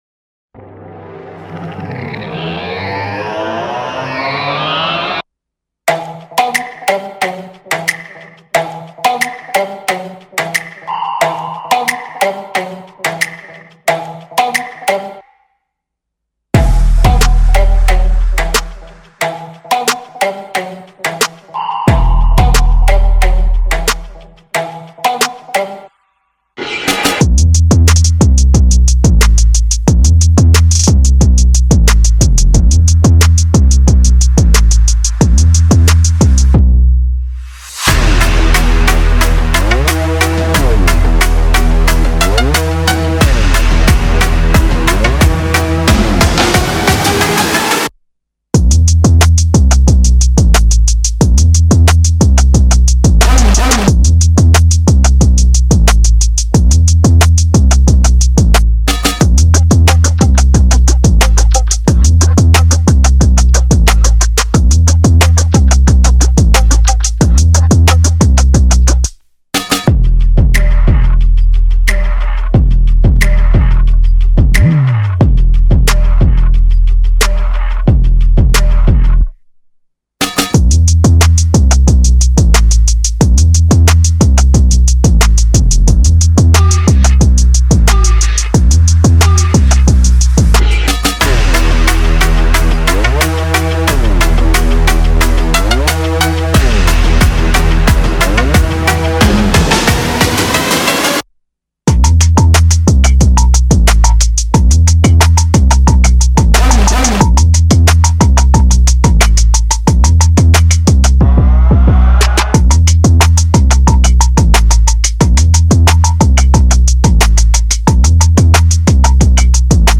K-Pop Instrumental